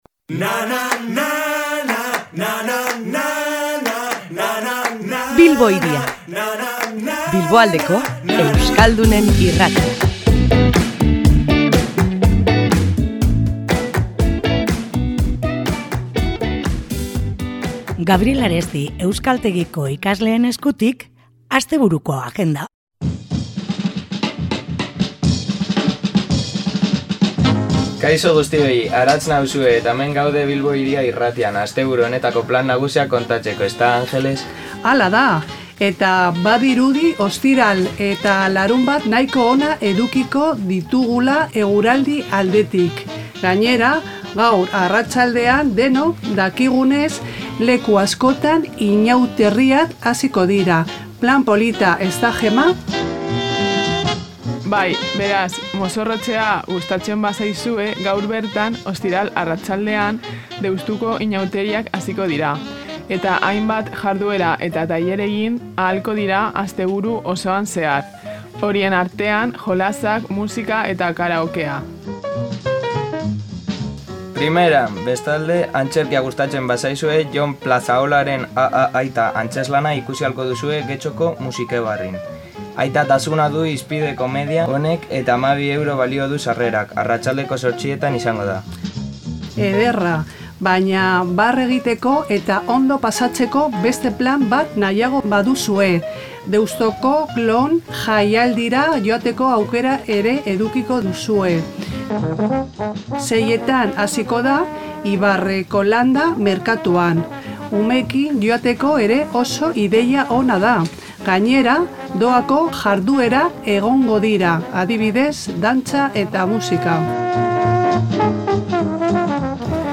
Asteburua ate joka dugu, eta ohikoa denez, agendari begirada bat botatzeko unea iritsi da. Gaur, Gabriel Aresti euskaltegiko ikasleak izan ditugu Bilbo Hiria irratian, eta haiek ekarritako proposamenekin astebururako plan erakargarriak mahai gainean jarri ditugu.